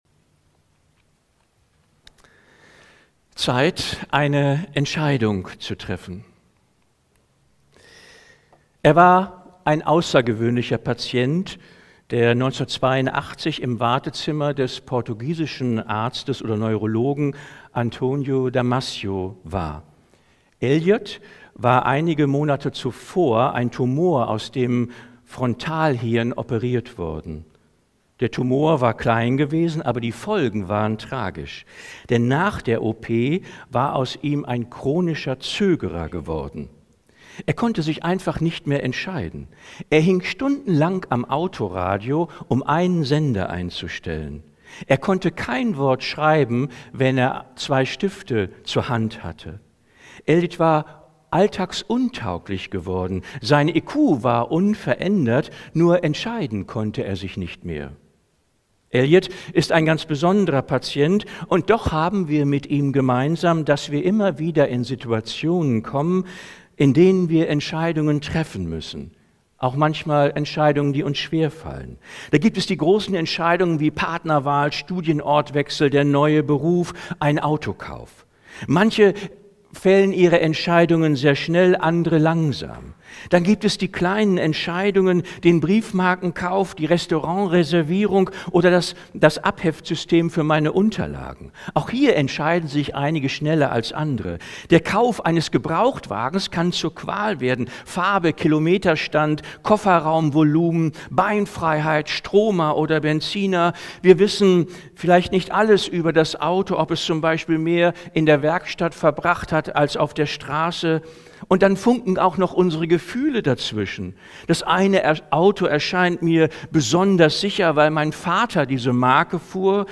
Predigten aus der Baptistengemeinde Leer